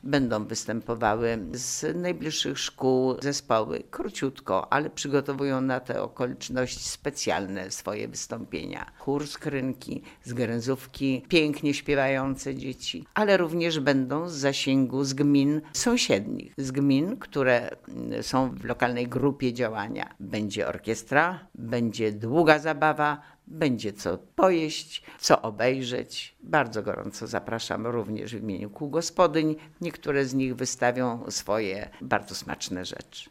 Wójt Gminy Łuków Kazimiera Goławska przyznaje, że na najbliższą niedzielę nie może zapewnić dobrej pogody, ale zapewnia, że atrakcji nie zabraknie: